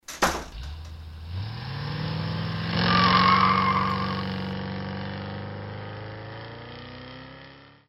This is the cue he mentioned, kept low to underscore what she as saying and not intrude.